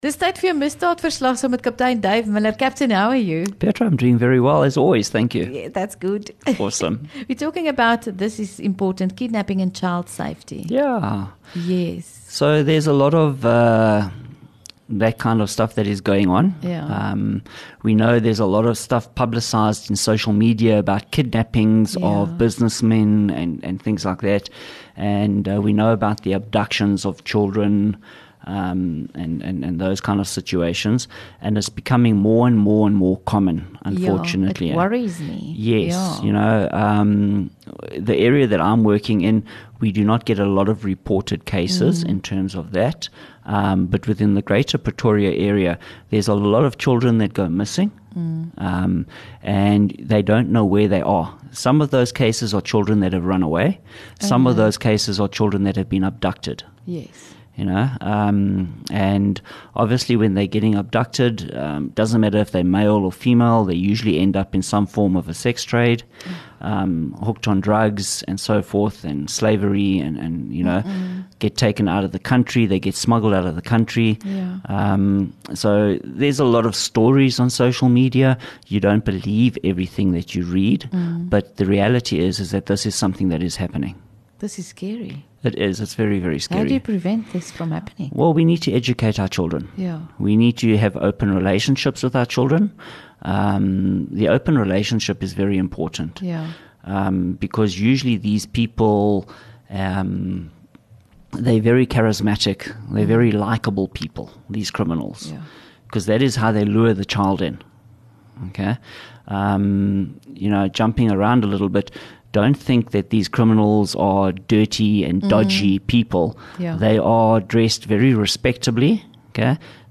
LEKKER FM | Onderhoude 12 Nov Misdaad Verslag